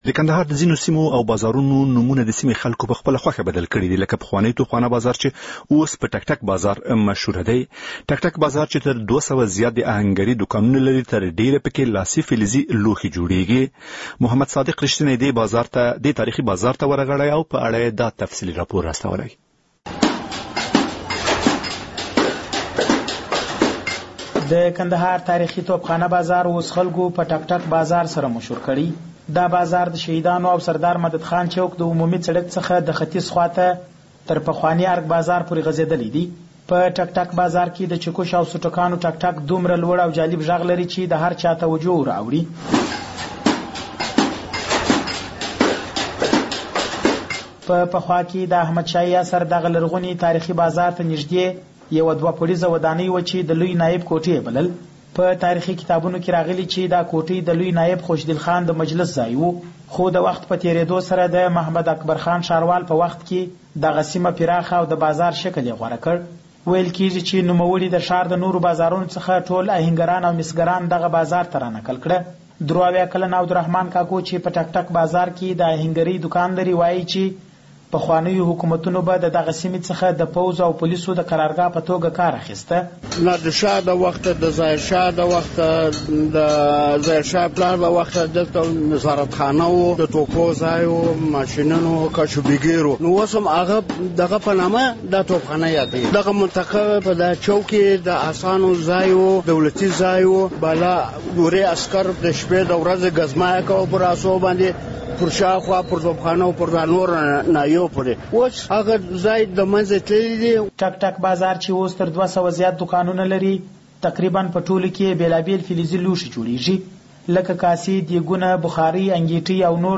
په ټک ټک بازار کې د چکوش او سوټکانو ټک ټک دومره لوړ او جالب ږغ لري چې د هرچا توجو ور اوړي....